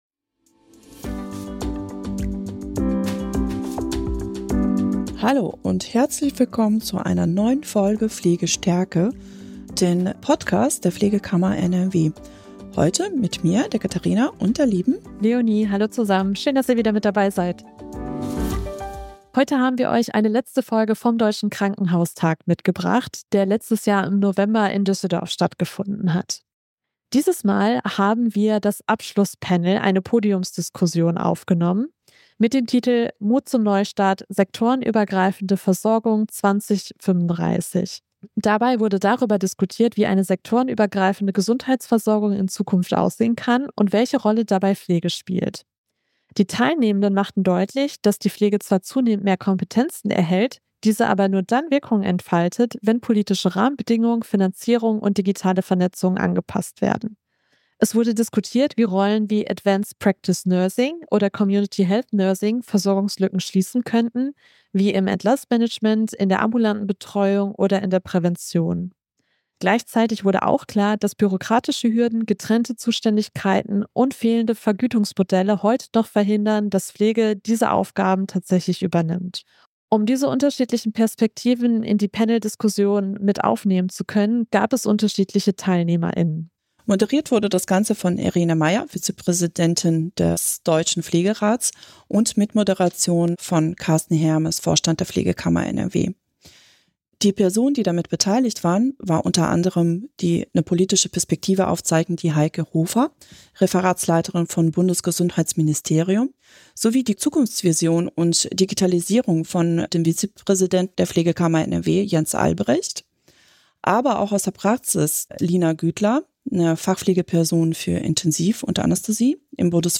Genau darum geht es in dieser Folge, in der der dritte und letzte Block des Deutschen Krankenhaustags im Mittelpunkt steht. Diskutiert wird eine der entscheidenden Zukunftsfragen im Gesundheitswesen: Wie kann sektorenübergreifende Versorgung bis 2035 gelingen?
Gemeinsam diskutieren Vertreter*innen aus Politik, Pflegepraxis und Selbstverwaltung darüber, welche gesetzlichen, strukturellen und professionellen Voraussetzungen notwendig sind, damit Pflege ihre Kompetenzen sektorenübergreifend wirksam einbringen kann.